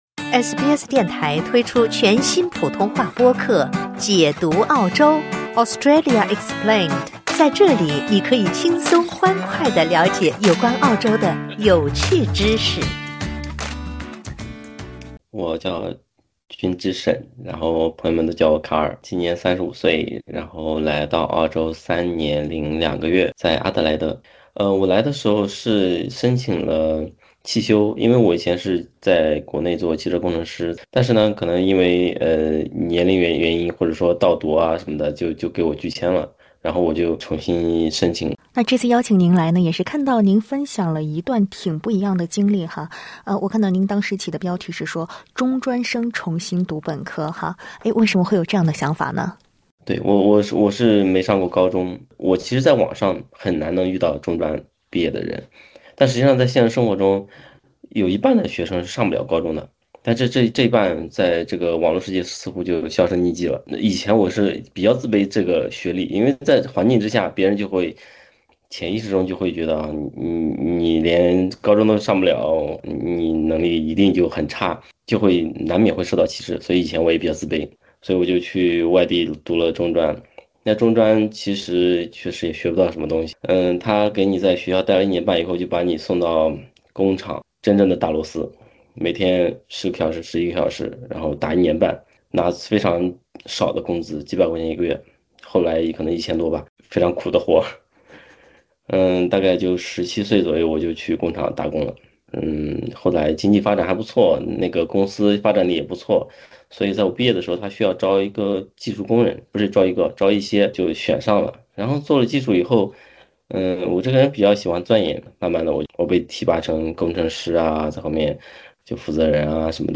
请点击完整音频报道： LISTEN TO 35岁、放下扳手、重返大学：一名“中专生”的第二次起跑 SBS Chinese 13:44 cmn 欢迎下载应用程序SBS Audio，订阅Mandarin。